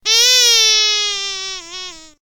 clock06.ogg